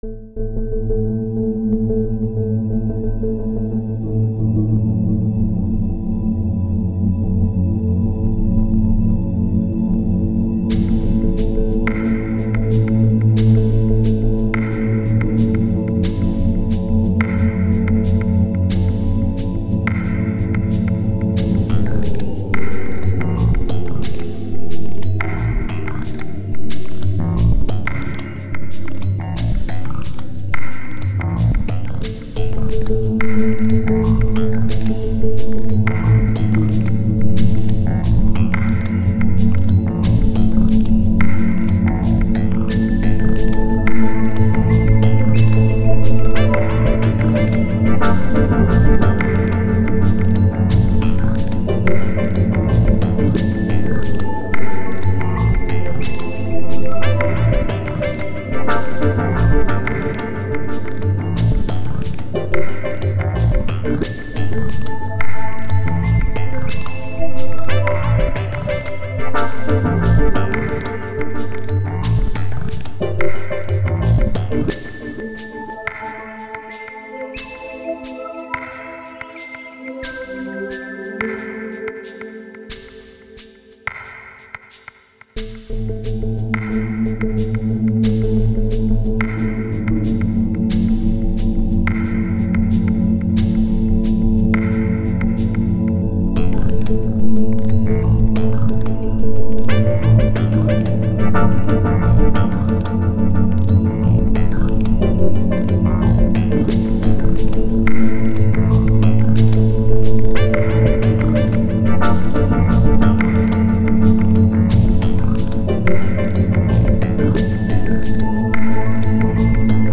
dance/electronic
Weird little thing.
Leftfield/noise
Ambient